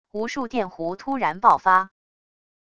无数电弧突然爆发wav音频